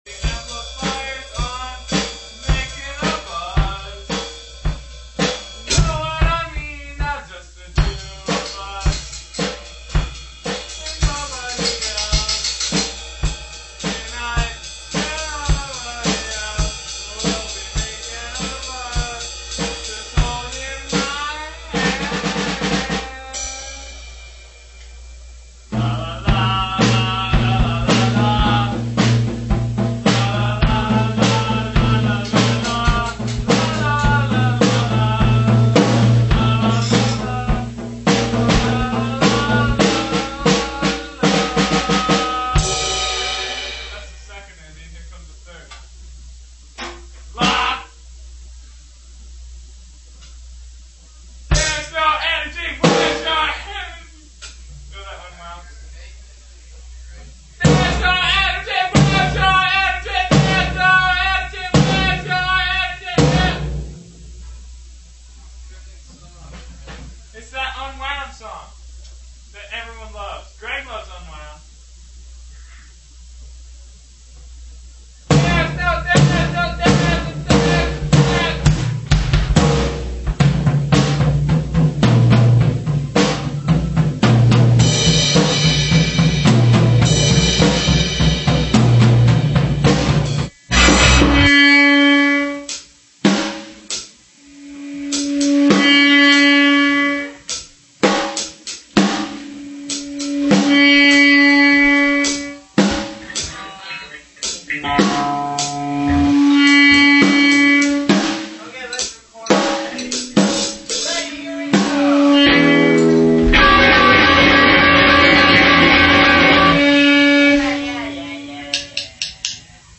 bass
drums
guitar + vocals
from when everything was set up in the backroom: shiny floor